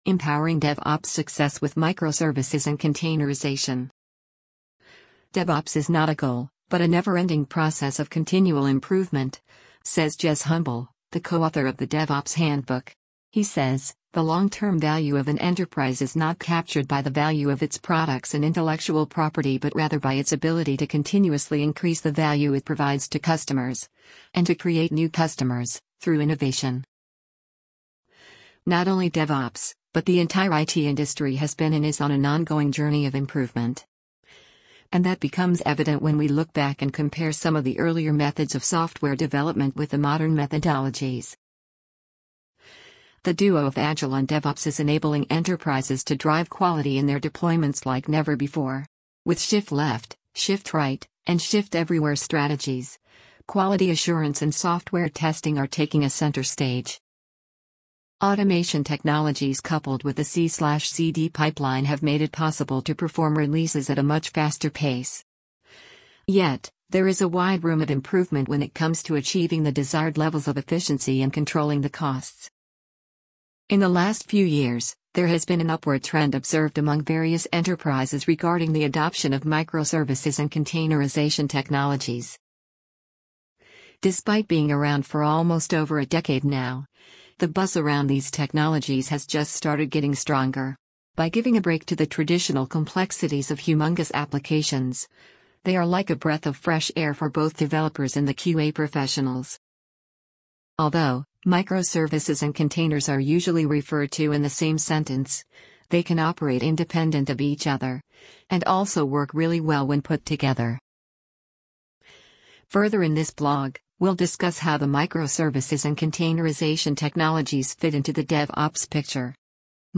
amazon_polly_14939.mp3